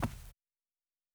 Footstep Carpet Walking 1_03.wav